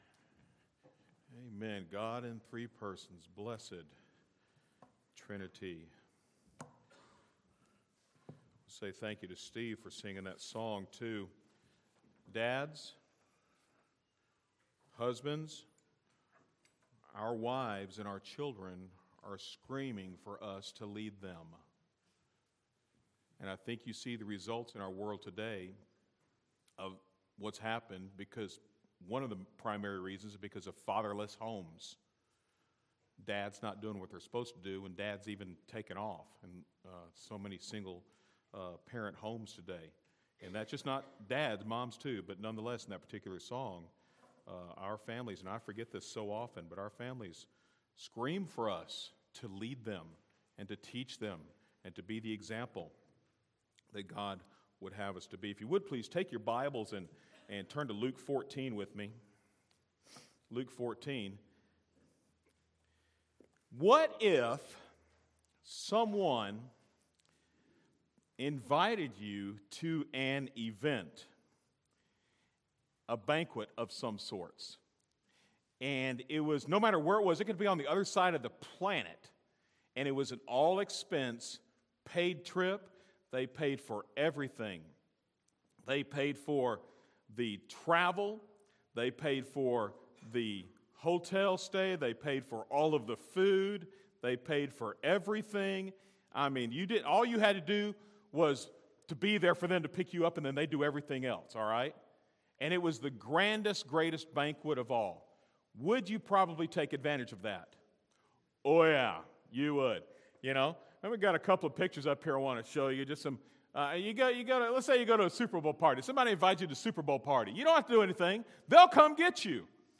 A message from the series